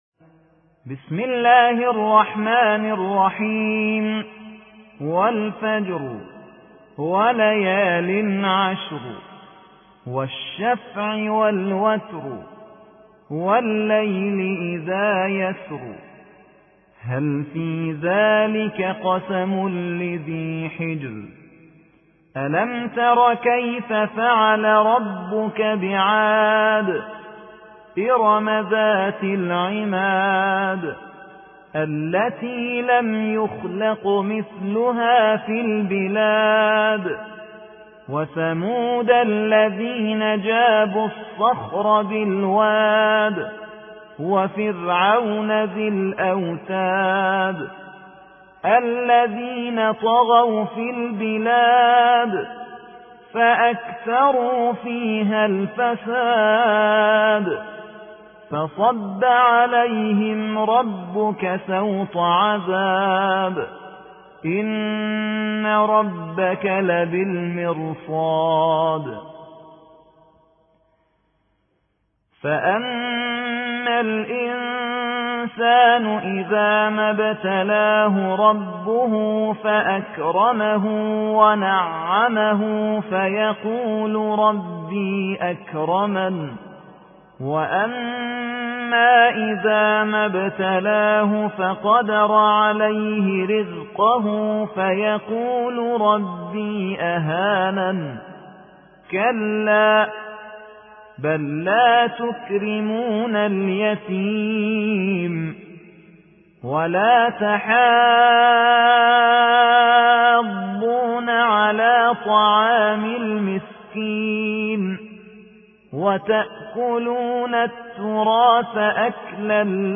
89. سورة الفجر / القارئ